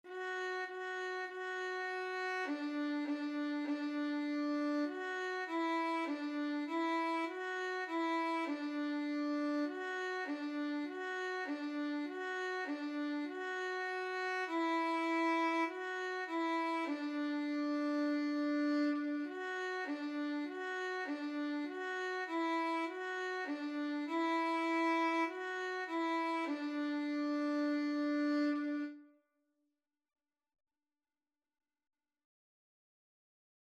4/4 (View more 4/4 Music)
D5-F#5
Violin  (View more Beginners Violin Music)
Classical (View more Classical Violin Music)